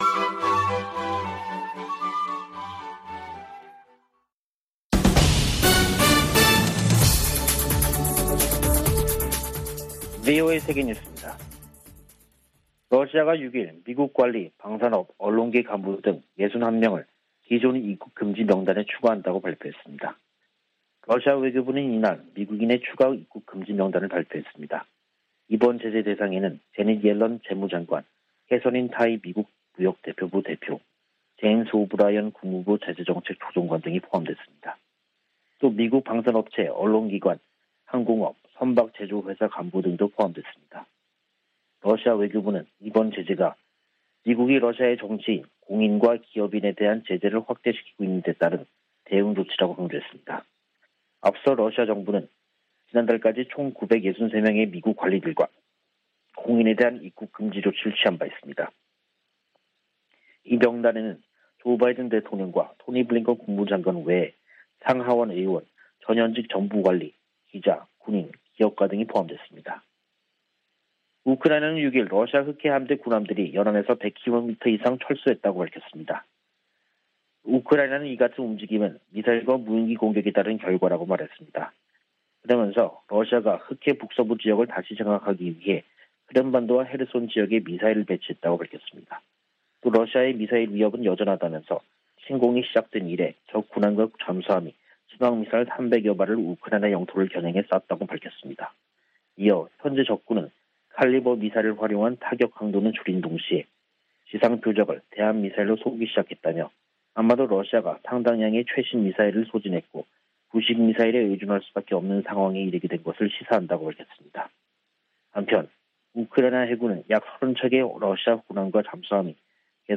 VOA 한국어 간판 뉴스 프로그램 '뉴스 투데이', 2022년 6월 7일 3부 방송입니다. 북한이 7차 핵실험을 감행할 경우 미국과 한국은 신속하고 강력한 대응을 할 것이라고 서울을 방문중인 웬디 셔먼 미국 국무부 부장관이 경고했습니다. 국제원자력기구는 북한 풍계리에서 핵실험을 준비 징후를 포착했다고 밝혔습니다. 미국의 전문가들은 북한이 최근 8발의 단거리탄도미사일을 발사한 것은 전시 한국에 기습 역량을 과시하려는 것이라고 분석했습니다.